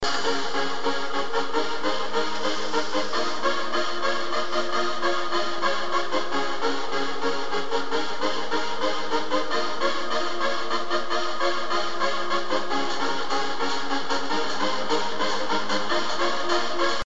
Old trance tune (very difficult to ID)